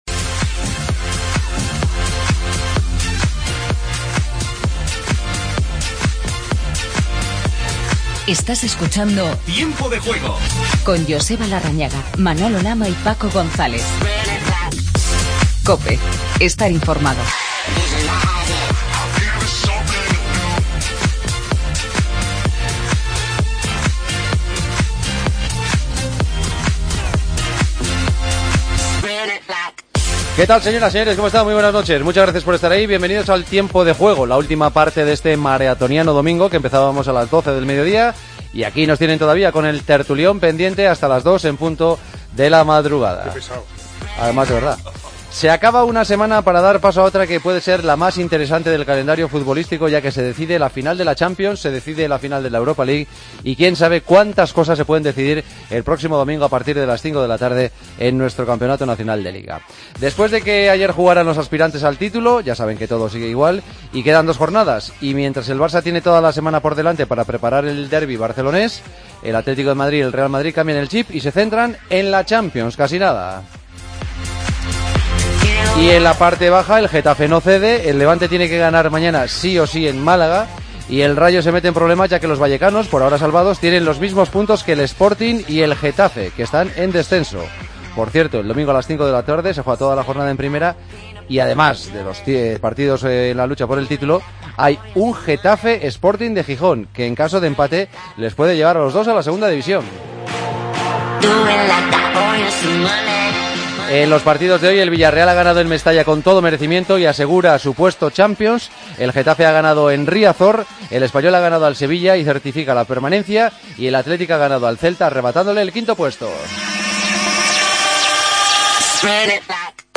Entrevistas a Marcelino y a Pau López.